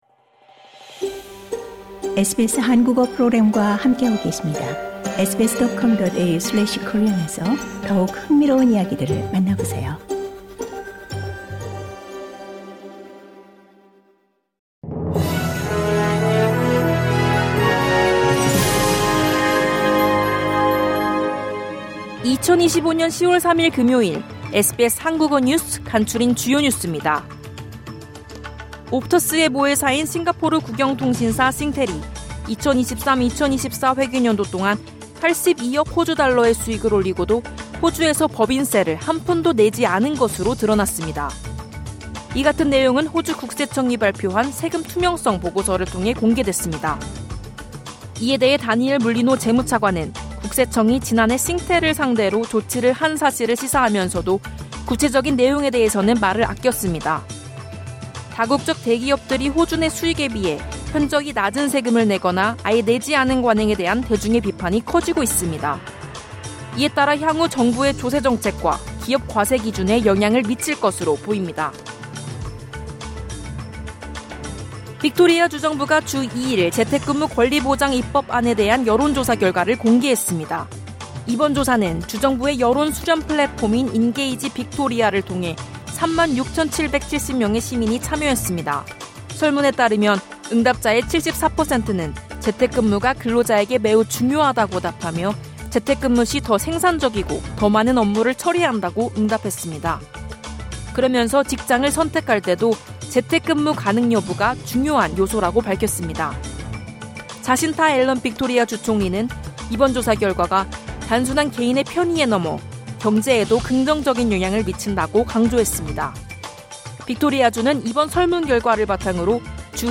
호주 뉴스 3분 브리핑: 2025년 10월 3일 금요일